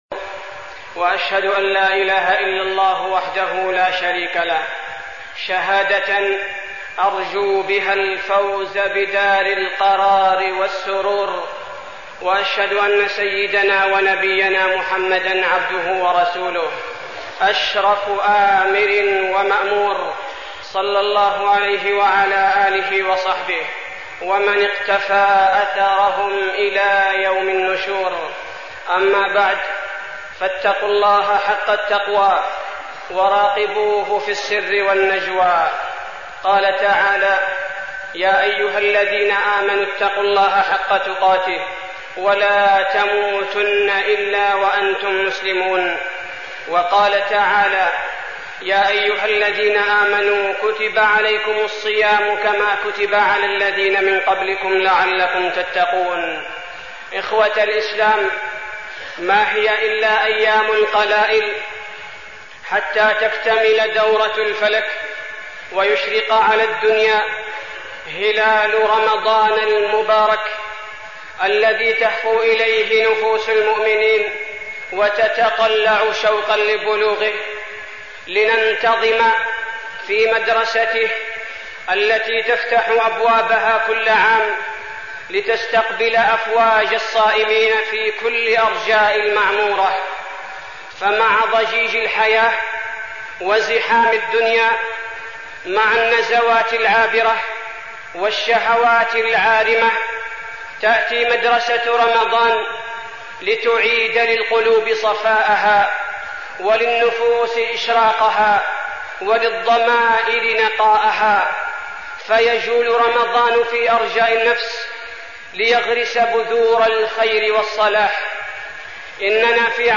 تاريخ النشر ٢٤ شعبان ١٤١٧ هـ المكان: المسجد النبوي الشيخ: فضيلة الشيخ عبدالباري الثبيتي فضيلة الشيخ عبدالباري الثبيتي فضائل شهر رمضان